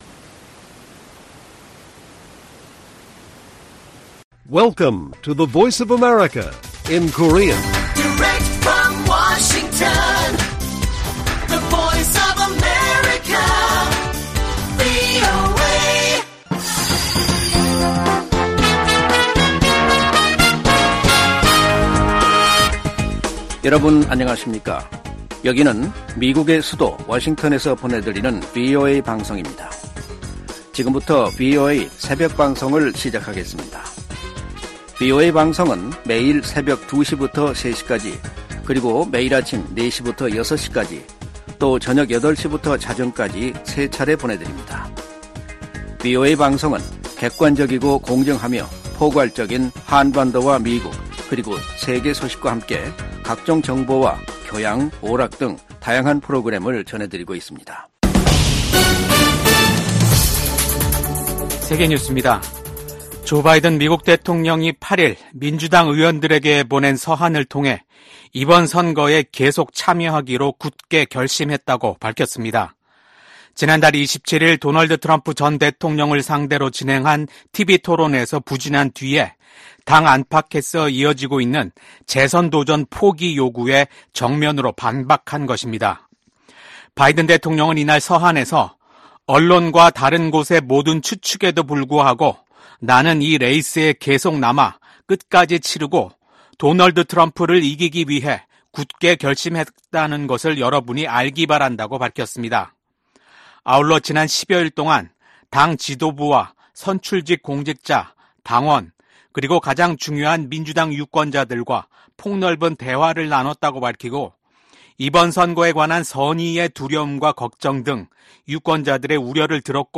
VOA 한국어 '출발 뉴스 쇼', 2024년 7월 9일 방송입니다. 북한의 핵과 미사일 프로그램이 주변국과 세계안보에 대한 도전이라고 나토 사무총장이 지적했습니다. 윤석열 한국 대통령은 북-러 군사협력이 한반도와 국제사회에 중대한 위협이라며 한-러 관계는 전적으로 러시아에 달려 있다고 말했습니다. 미국 고위관리는 중국의 러시아 지원이 전쟁을 부추기고 있으며, 워싱턴 나토 정상회의에서 이 문제가 중요하게 논의될 것이라고 밝혔습니다.